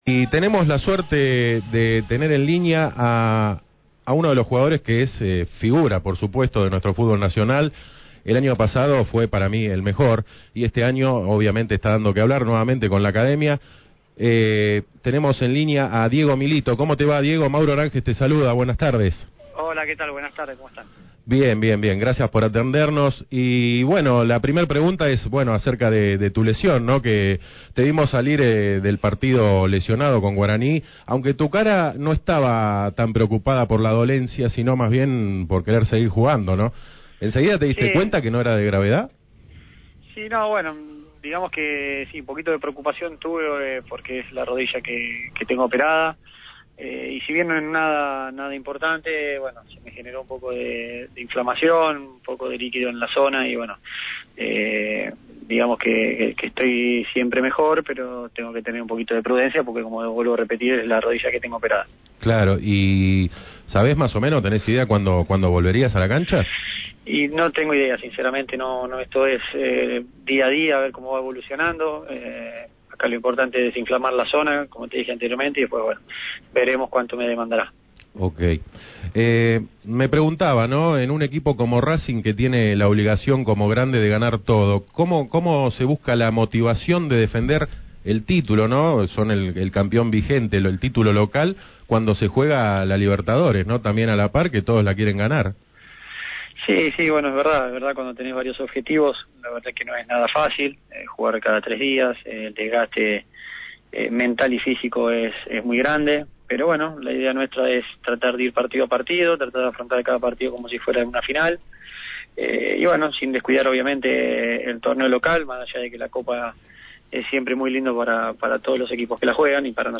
Así lo afirmó el capitán de la Academia en comunicación con Tocala x la Banda.